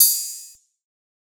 TS OpenHat_8.wav